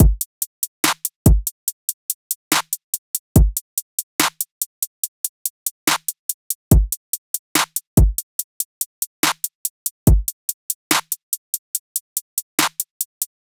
SOUTHSIDE_beat_loop_mafia_full_02_143.wav